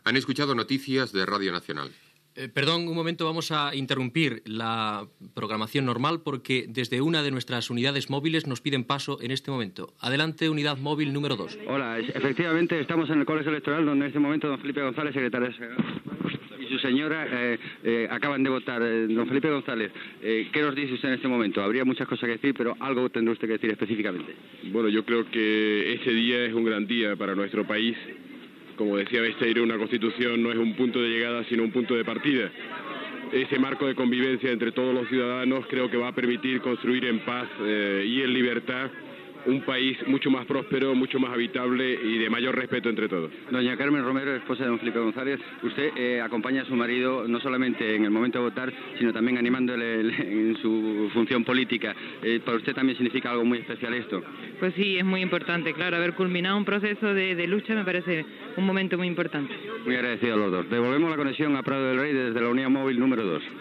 Dia del referèndum per aprovar la Constitució espanyola. Identificació del programa i connexió amb el col·legi electoral de Madrid on han votat Felipe González, líder del Partido Socialista Obrero Español, i de la seva esposa Carmen Romero
Informatiu